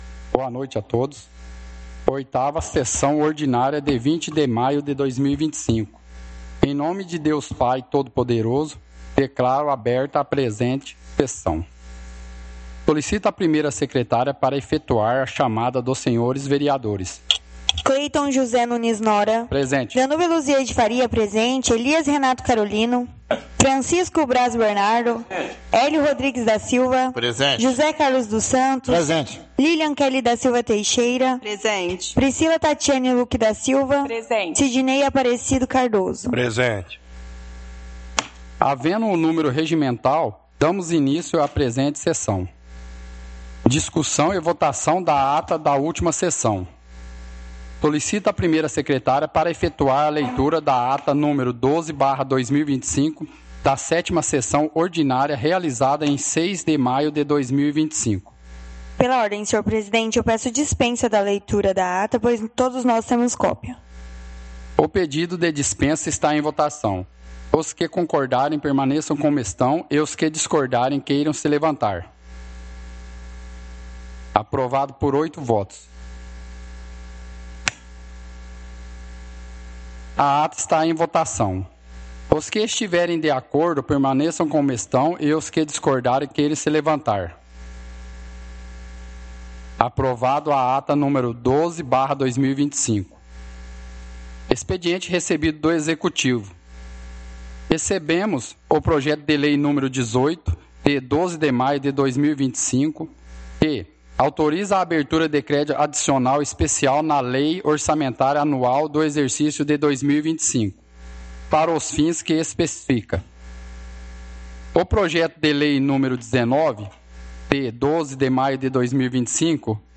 Áudio da 8ª Sessão Ordinária – 20/05/2025